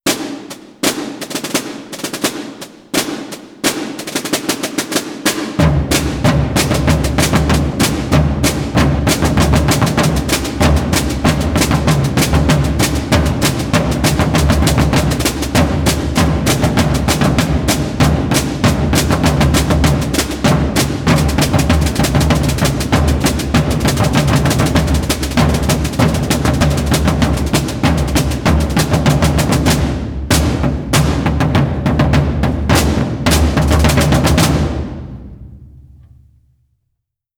4.2. TOC DE CERCAVILA DE LA VÍBRIA Víbria de Tarragona